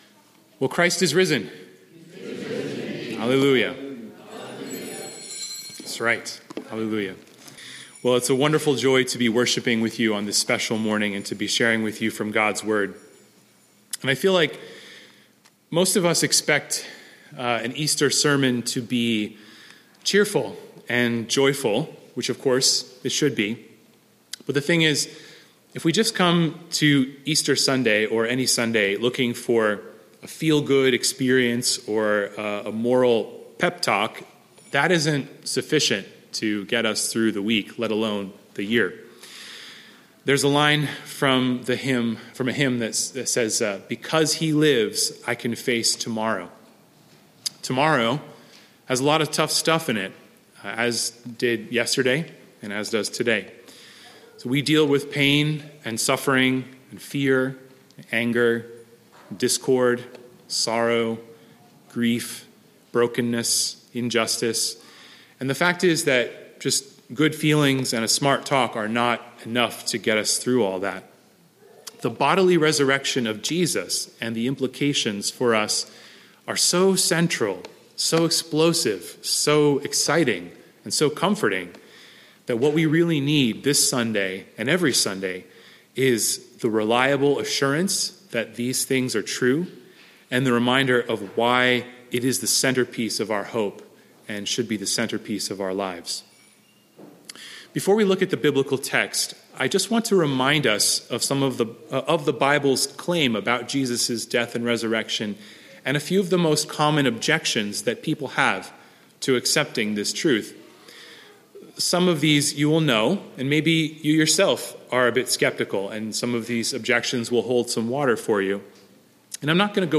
Resurrection Sunday
Sermon Text: Matthew 27:62–28:15